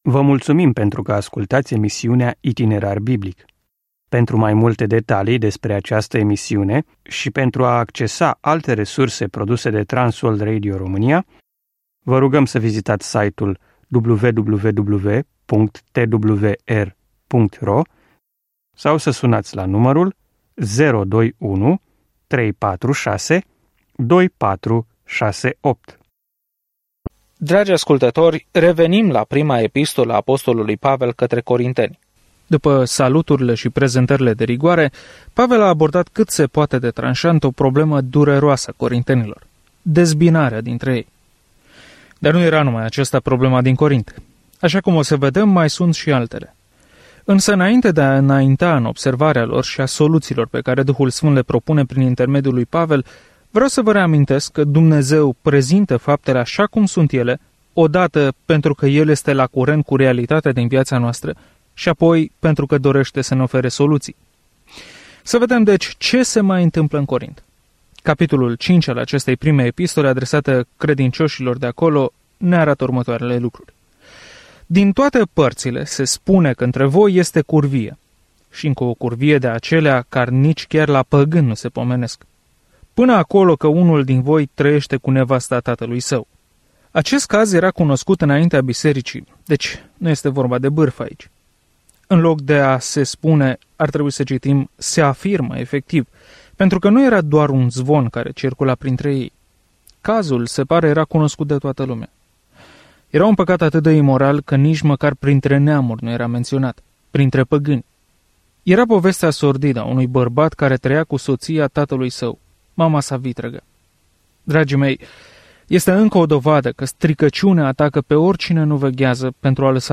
Este subiectul abordat în prima scrisoare către Corinteni, oferind îngrijire practică și corectare problemelor cu care se confruntă tinerii creștini. Călătoriți zilnic prin 1 Corinteni în timp ce ascultați studiul audio și citiți versete selectate din Cuvântul lui Dumnezeu.